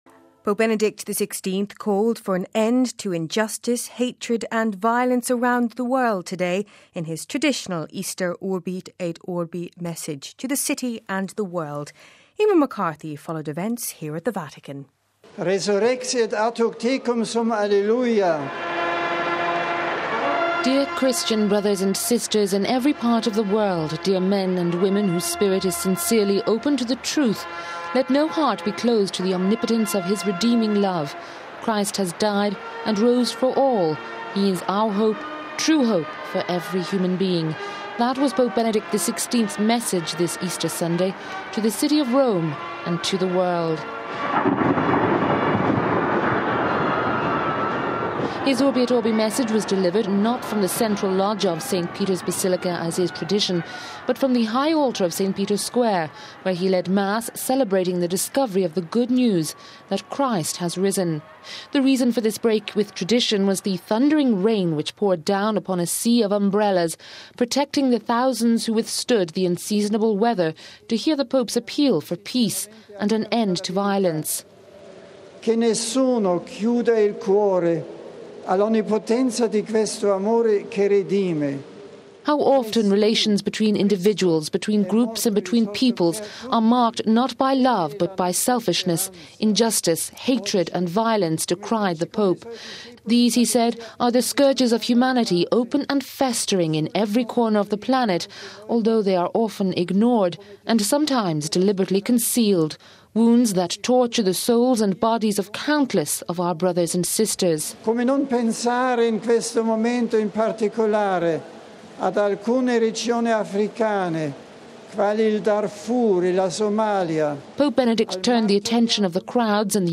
The reason for this break in tradition was the thundering rain which poured down upon a sea of umbrellas, protecting the thousands who withstood the unseasonable weather to hear the Pope’s appeal for peace and end to violence.
He then went on to wish the Christians across the world a Happy and peaceful Easter in over 60 languages, beginning with Italian, and including, not name but a few, Arabic, Chinese, Burmese, Hindi, Irish and concluding of course in Latin:
The Regina Coeli, which will accompany us for the Easter Season then sounded the end of Mass: